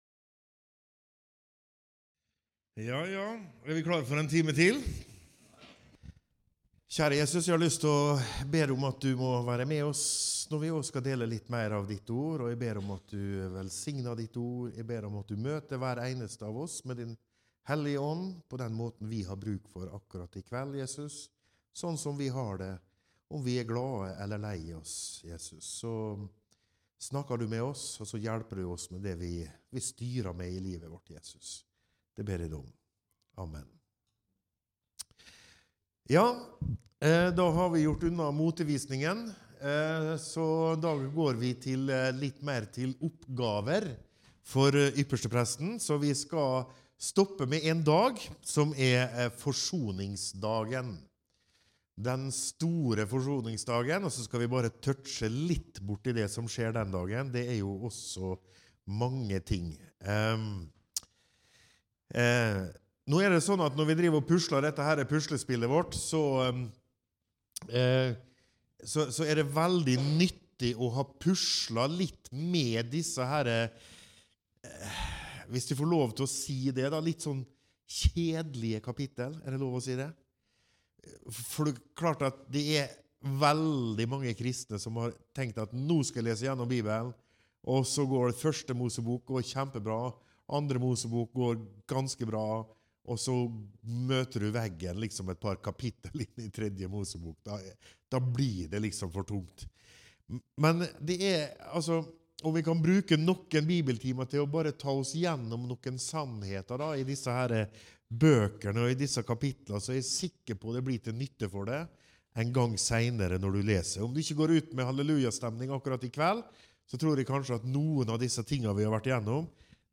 Fra møtehelg i November 2024 Andre del dreier seg om Den store forsoningsdagen.